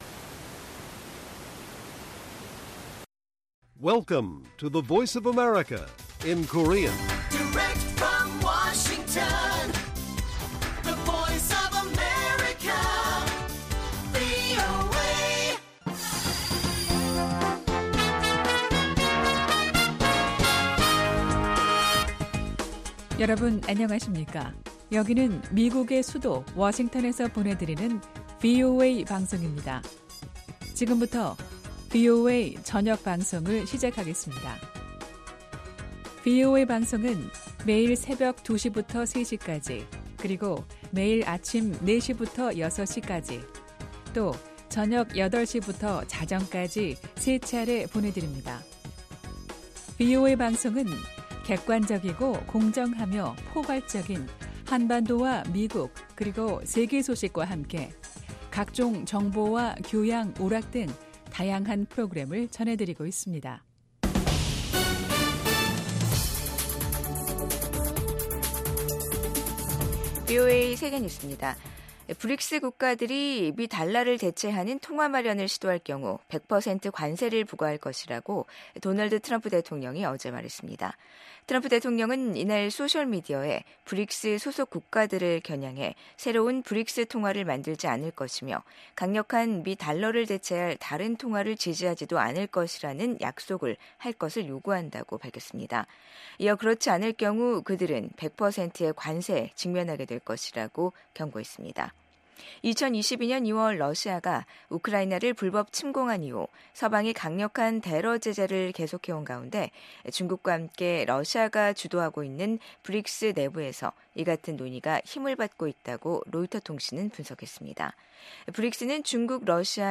VOA 한국어 간판 뉴스 프로그램 '뉴스 투데이', 2025년 1월 31일 1부 방송입니다. 트럼프 2기 행정부 국가정보국장으로 지명된 털시 개버드 전 하원의원은 미국이 북한의 핵과 미사일 위협을 낮추는 데 대북 정책의 우선순위를 둬야 한다고 밝혔습니다. 미국 육군장관 지명자는 북한, 중국, 러시아, 이란이 미국에 맞서 협력하고 있다고 지적했습니다.